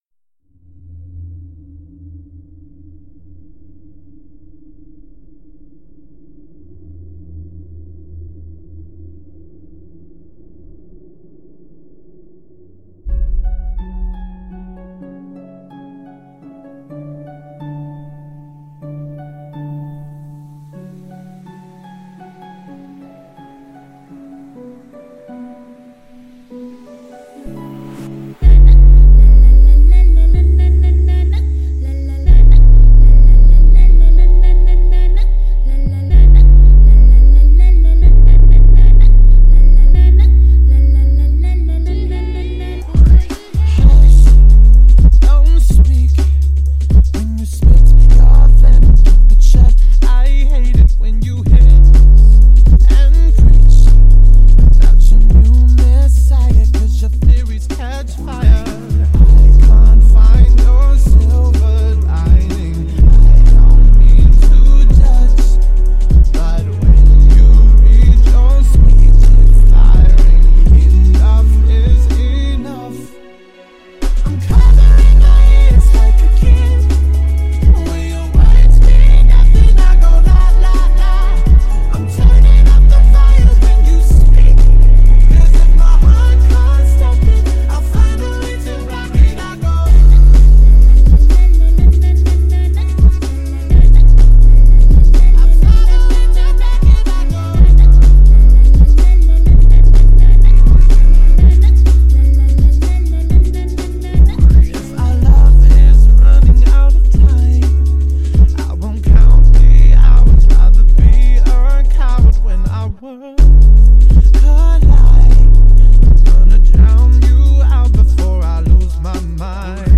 Dubstep Bass Boosted.